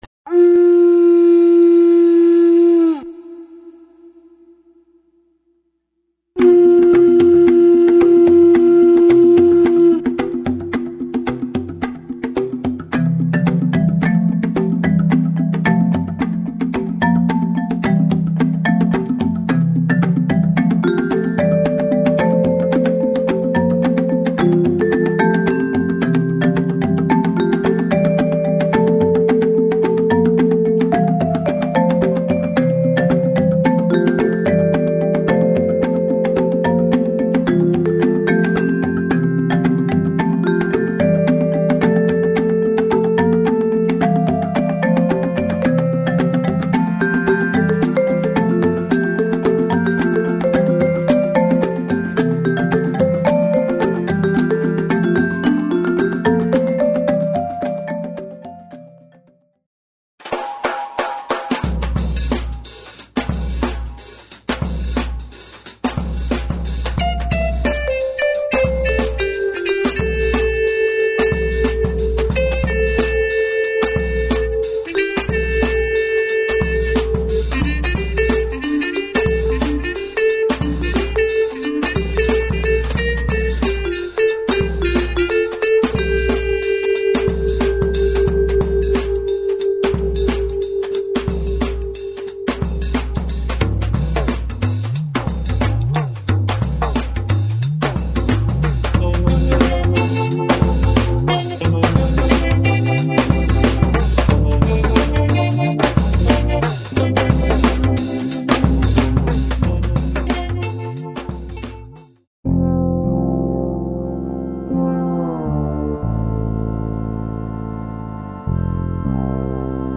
A percussion based journey of rhythm and melody